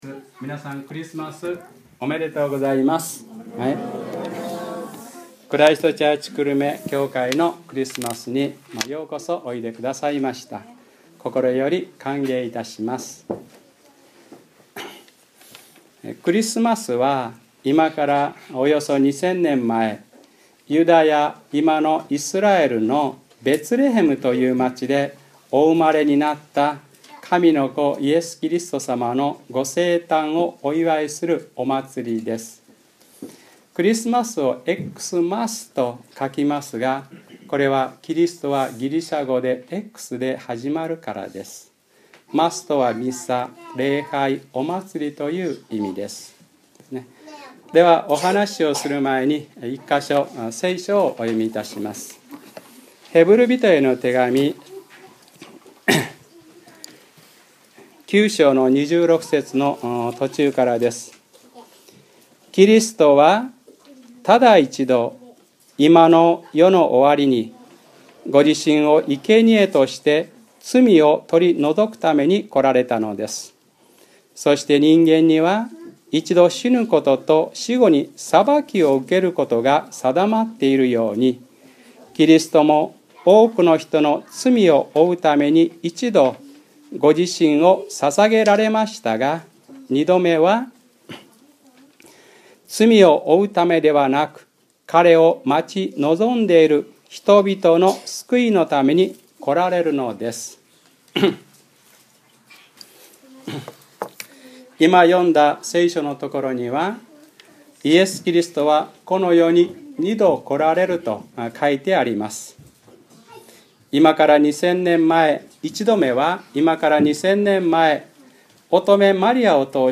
2012年12月23日(日）クリスマス礼拝 『二度のおとずれ』